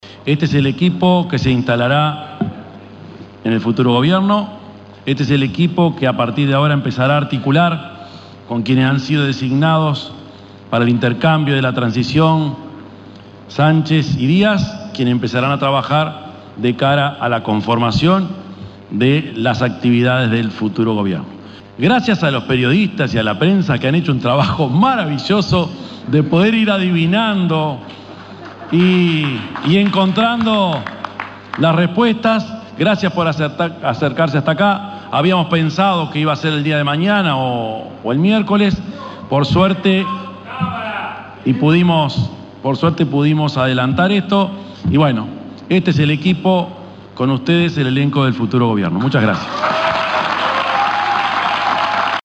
Orsi encabezó una conferencia de prensa en el mediodía de este lunes para anunciar su gabinete. La actividad fue en el hotel Radisson de Montevideo.